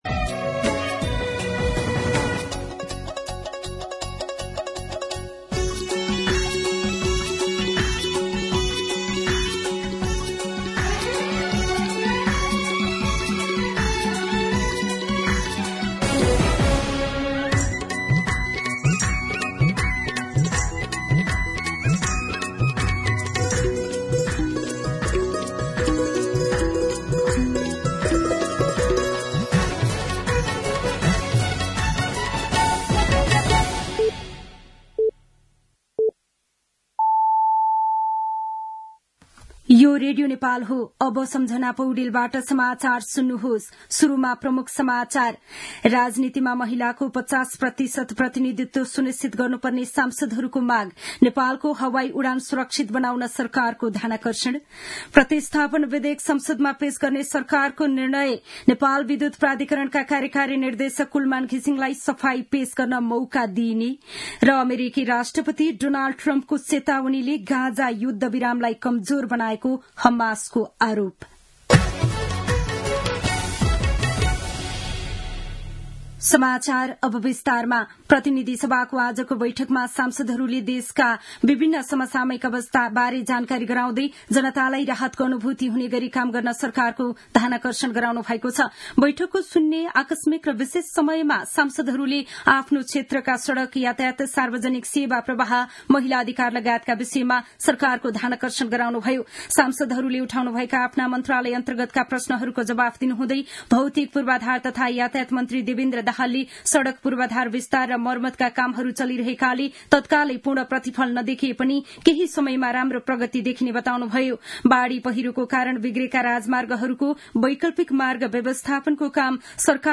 दिउँसो ३ बजेको नेपाली समाचार : २४ फागुन , २०८१
3pm-news.mp3